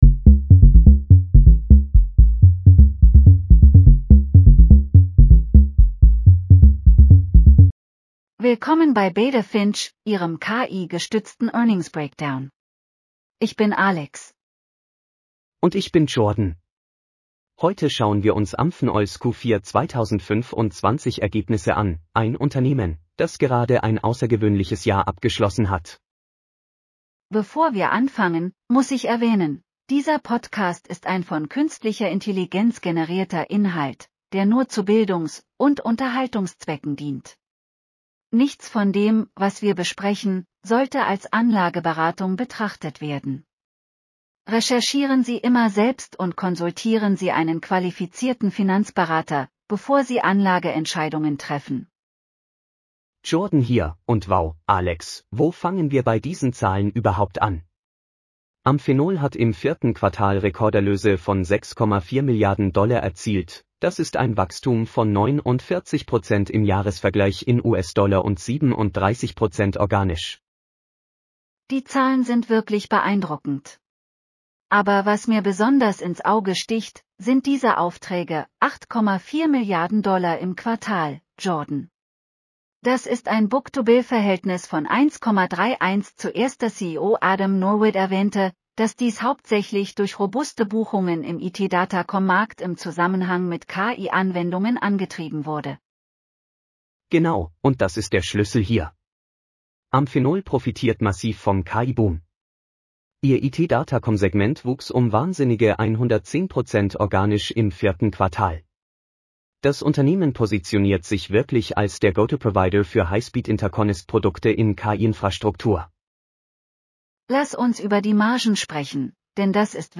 BETA FINCH PODCAST SCRIPT